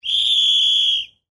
Звуки хоккея
Свисток рефери в хоккее при нарушении правил игры